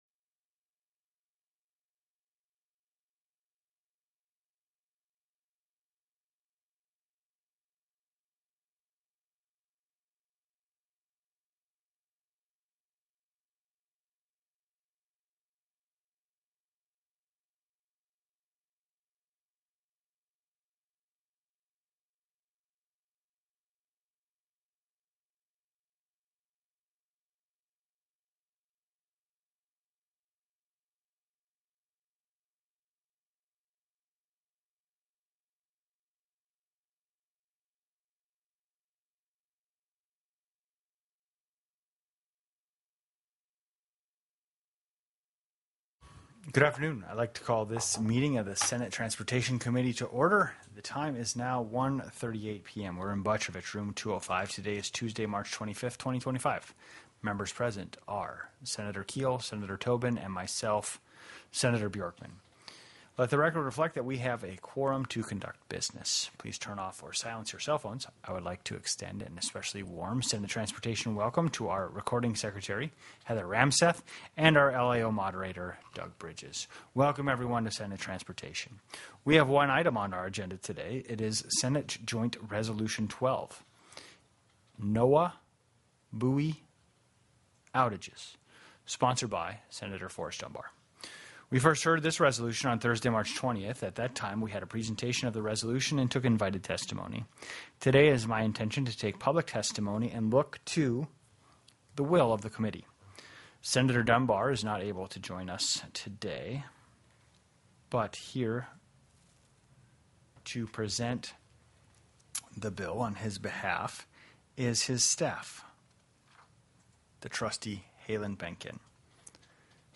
The audio recordings are captured by our records offices as the official record of the meeting and will have more accurate timestamps.
+ teleconferenced
-- Public Testimony --
Senator Mike Shower (via teleconference)